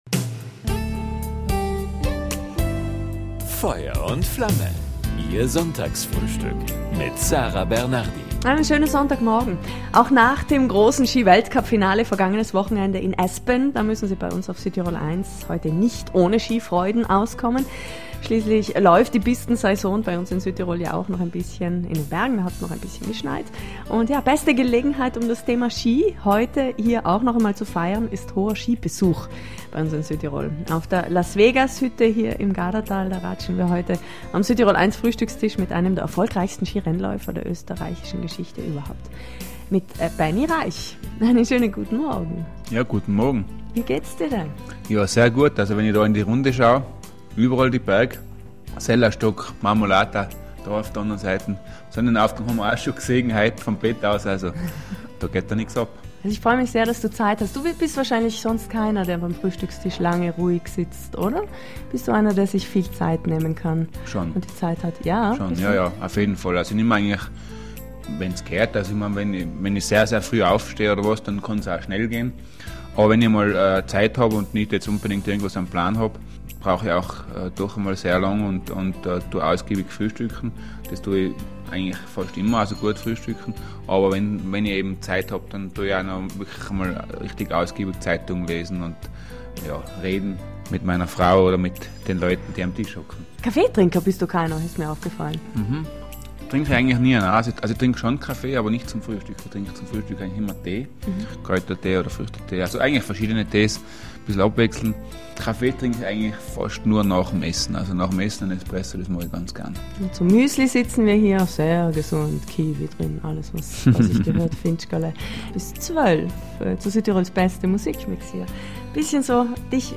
Lauter spannende Fragen an den „Blitz aus Pitz“ an diesem Sonntag im Südtirol1-Sonntagsfrühstück „Feuer und Flamme“ - einfach nachhören!